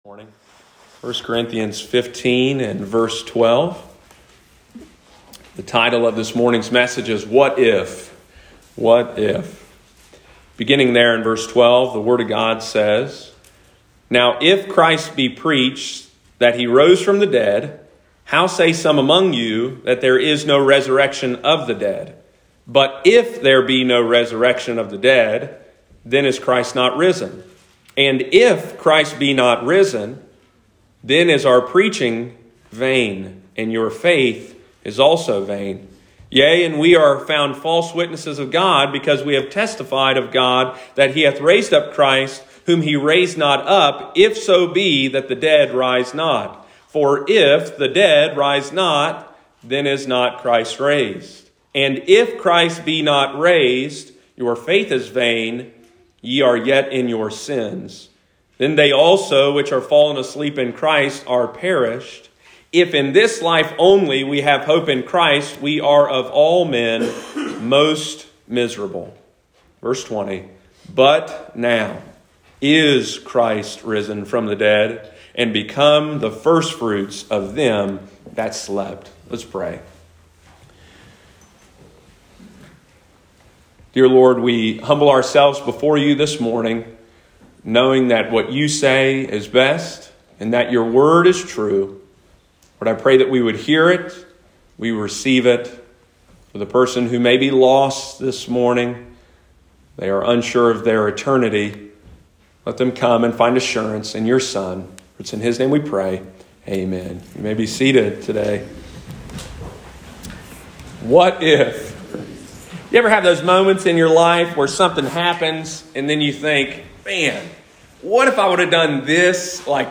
What if there is no resurrection from the dead? Some of the believers in Corinth claimed that no one but Jesus can be risen from the grave but they didn’t understand the implications of this belief. In today’s message in our First Corinthians Series we will as the question, “What if…?”